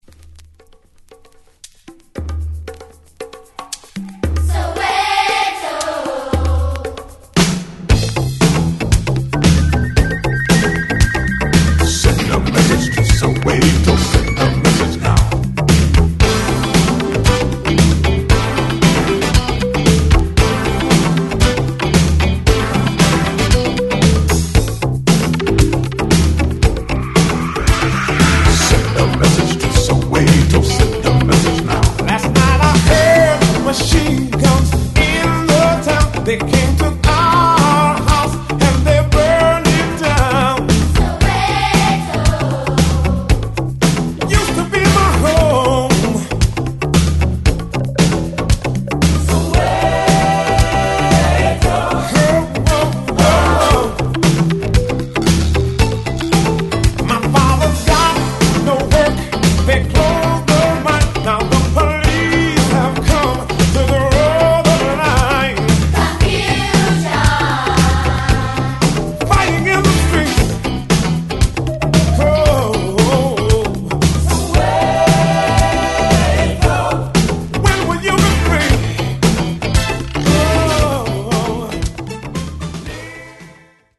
80's Electro Disco